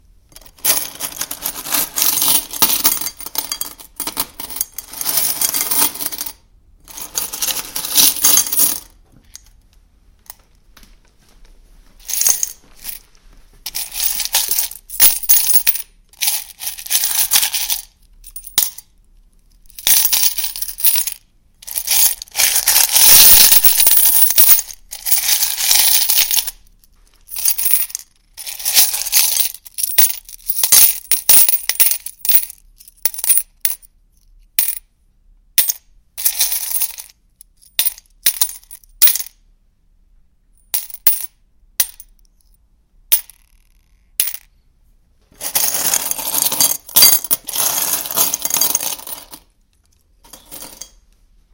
Coins in a Glass » COINS IN A GLASS 10
描述：Icelandic kronas being dropped into a glass.
标签： change quarter glass currency coins money dime coin
声道立体声